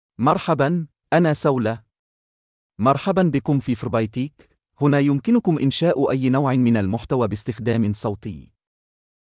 Saleh — Male Arabic (Yemen) AI Voice | TTS, Voice Cloning & Video | Verbatik AI
Saleh is a male AI voice for Arabic (Yemen).
Voice sample
Listen to Saleh's male Arabic voice.
Saleh delivers clear pronunciation with authentic Yemen Arabic intonation, making your content sound professionally produced.